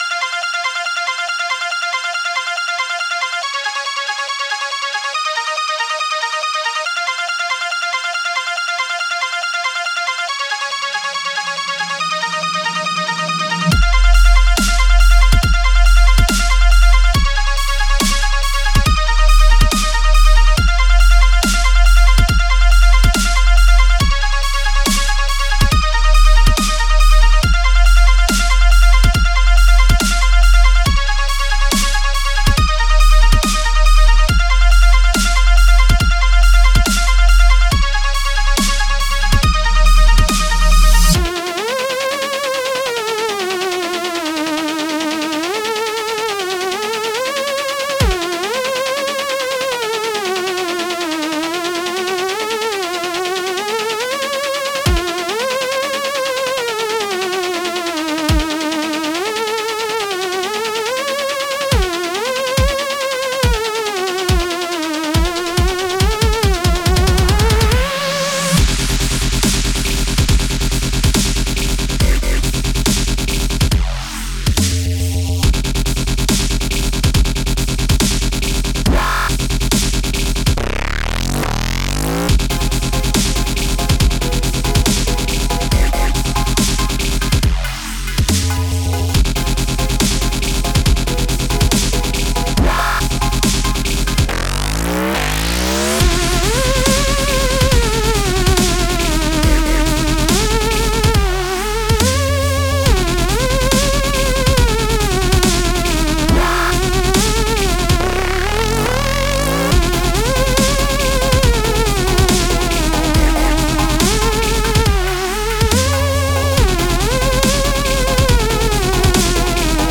Dubstep/ 140 BPM
edm
electronic